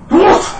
sneeze1.wav